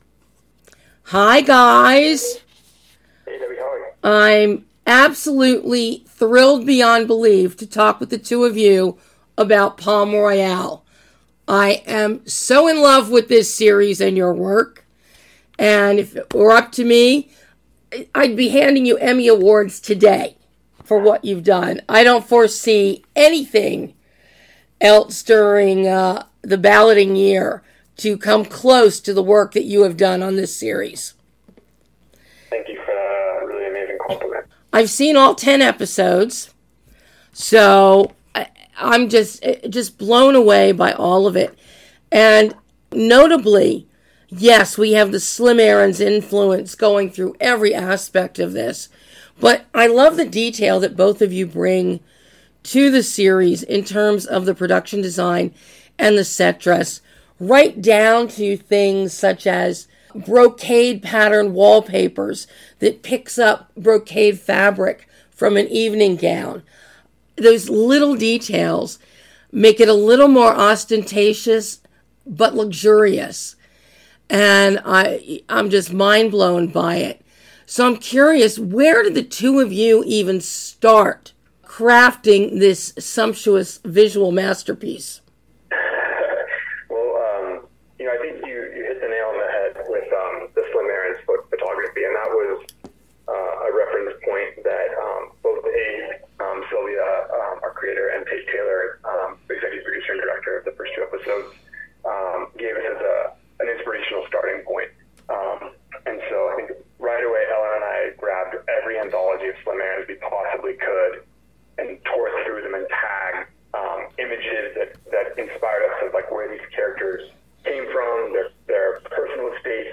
PALM ROYALE - Exclusive Interview